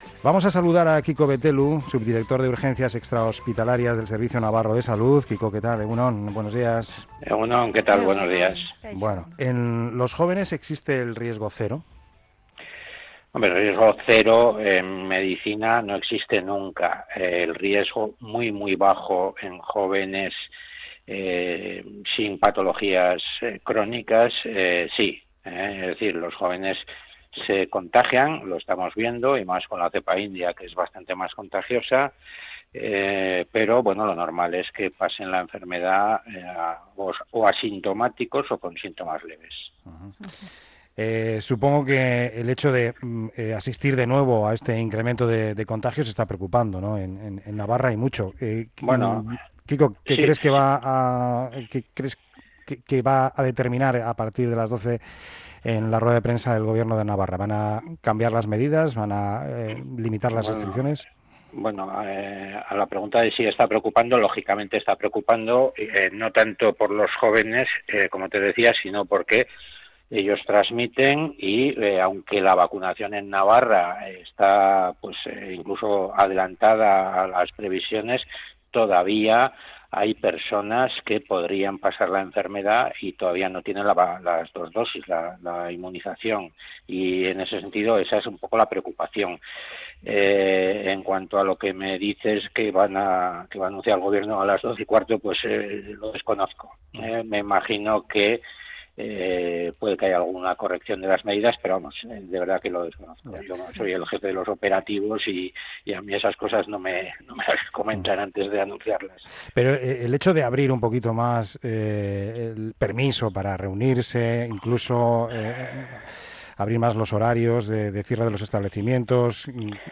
Entrevistado en Onda Vasca, ha subrayado que la mayor parte de los positivos detectados relacionados con el brote están saliendo gracias a los diferentes cribados puestos en marcha el viernes. Reconoce que estamos ante un repunte "espectacular" y se muestra preocupado.